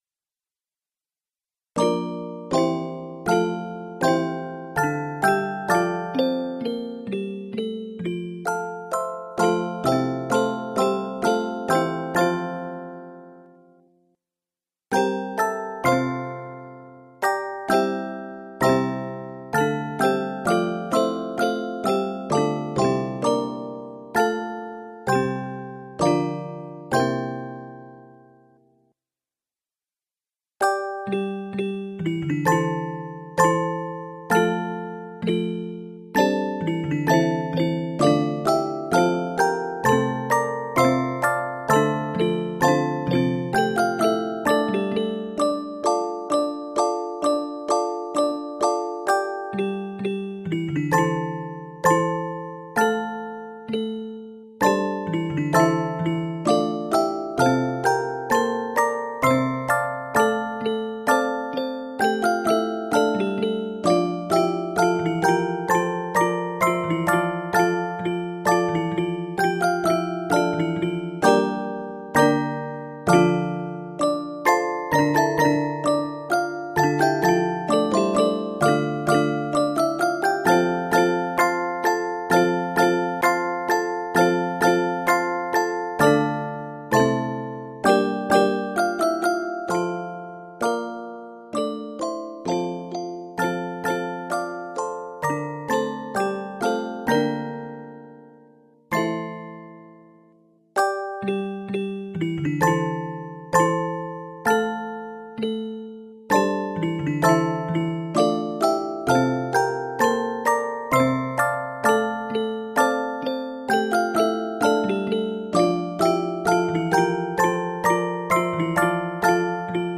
N/A Octaves: 3 Level